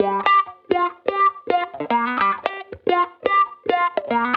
Index of /musicradar/sampled-funk-soul-samples/110bpm/Guitar
SSF_StratGuitarProc1_110G.wav